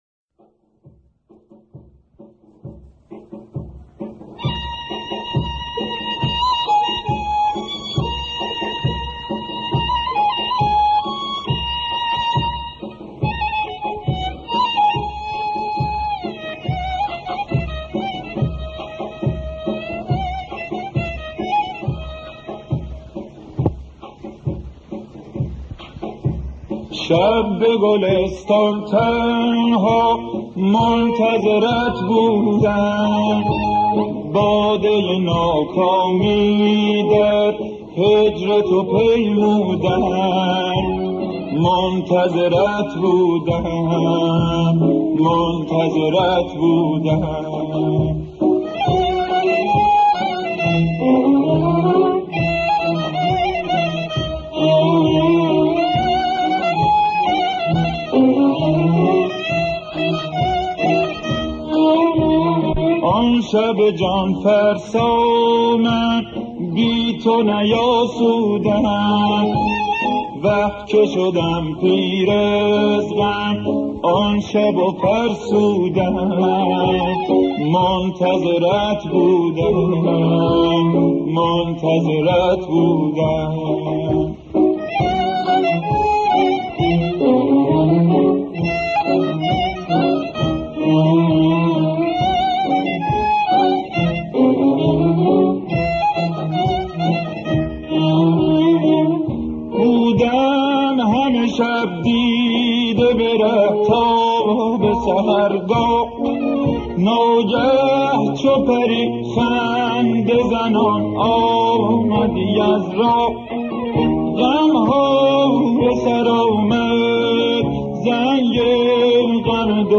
ترانه سنتی
موسیقی سنتی